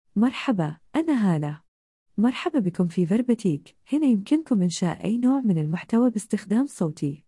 HalaFemale Gulf Arabic AI voice
Hala is a female AI voice for Gulf Arabic.
Voice sample
Listen to Hala's female Gulf Arabic voice.
Female
Hala delivers clear pronunciation with authentic Gulf Arabic intonation, making your content sound professionally produced.